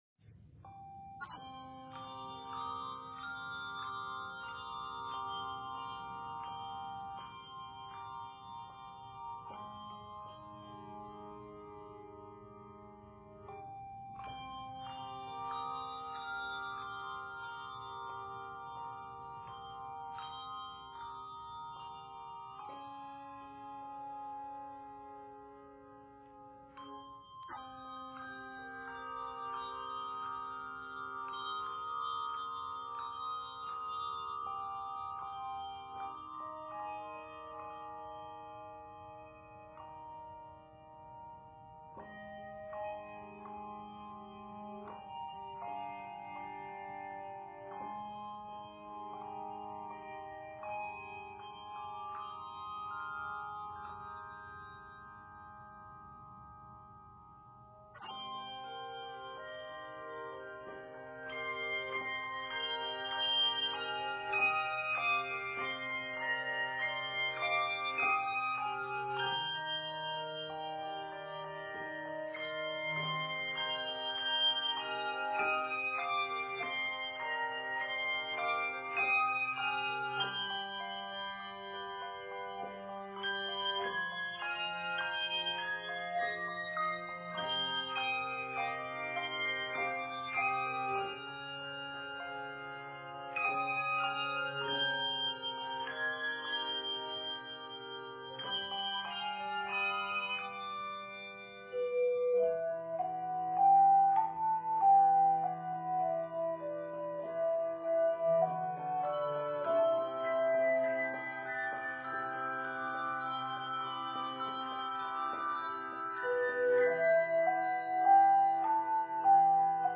richly harmonized medley
handbell choir